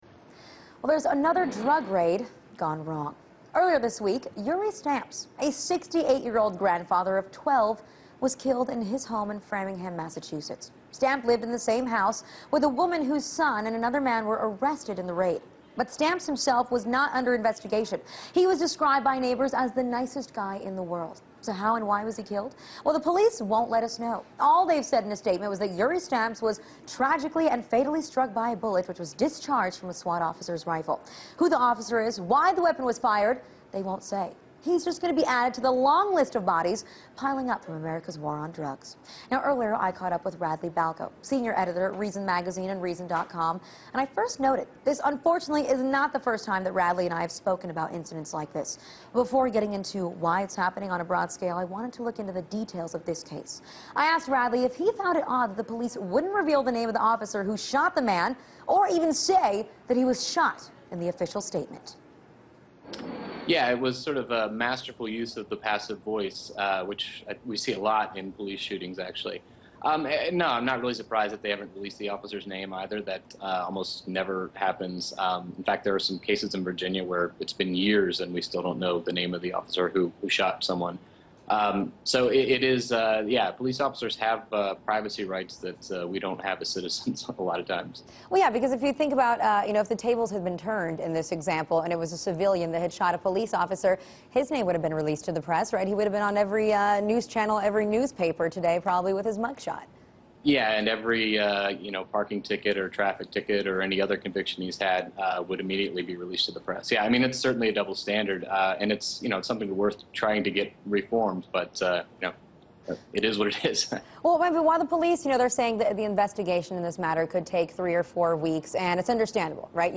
Radley Balko Discusses the Latest Tragedy in the Drug War on Russia Today